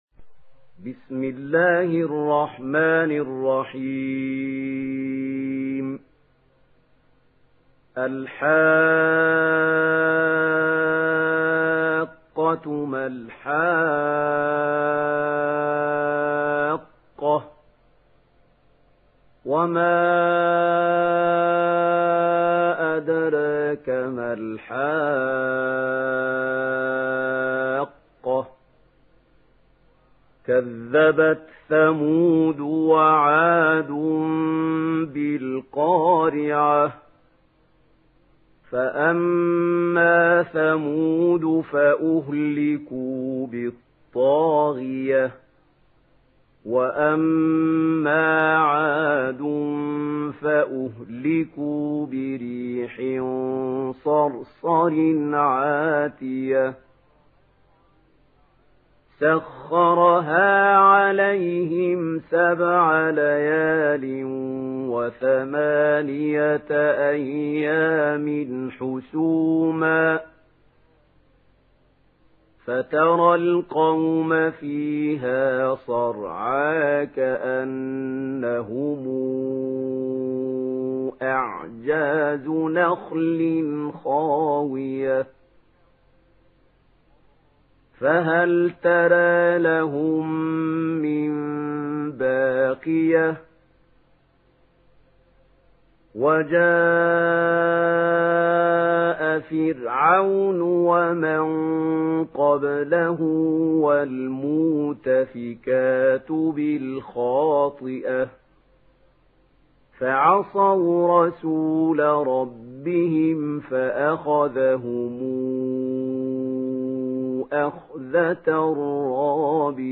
دانلود سوره الحاقه mp3 محمود خليل الحصري روایت ورش از نافع, قرآن را دانلود کنید و گوش کن mp3 ، لینک مستقیم کامل